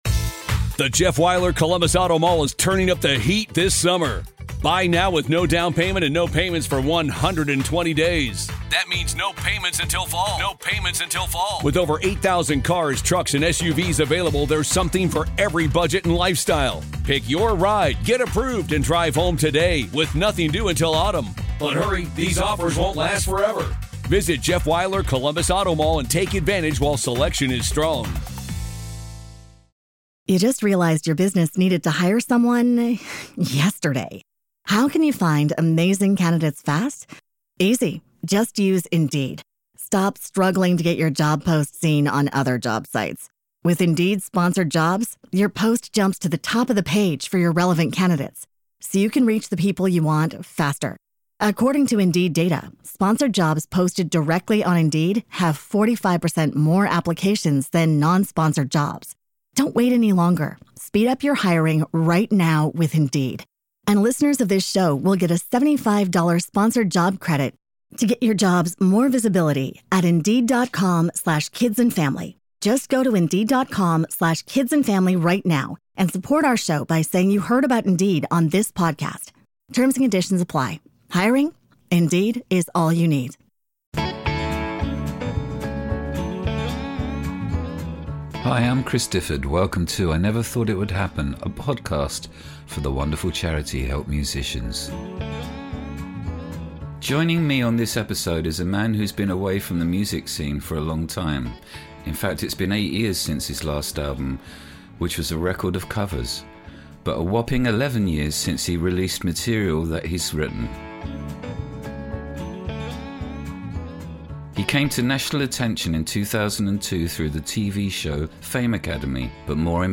talks to the singer-songwriter Lemar who was first introduced to music fans via the BBC television show Fame Academy.